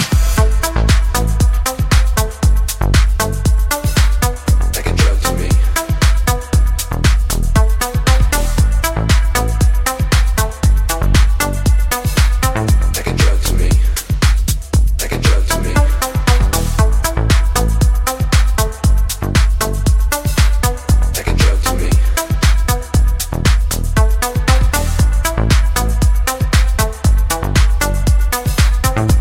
• Качество: 128, Stereo
dance
club